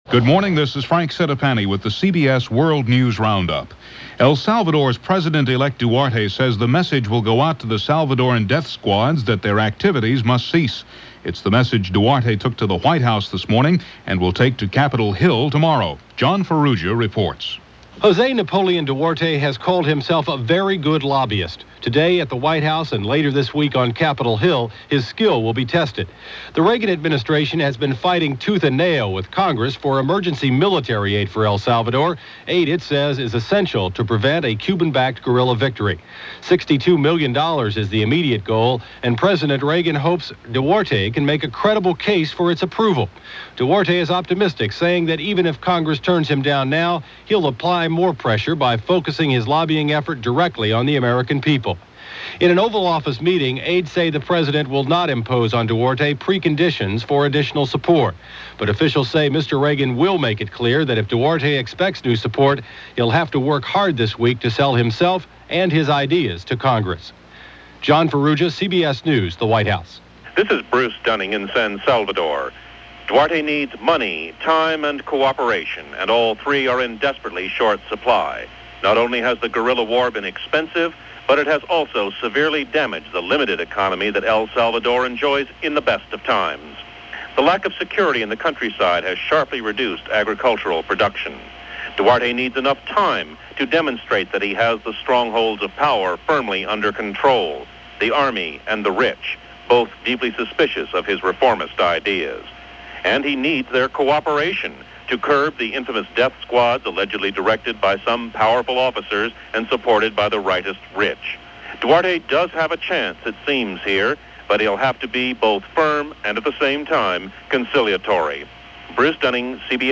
A mess of a day – and there was a lot more to it, as reported by The CBS World News Roundup for May 20, 1984 – thirty years ago today.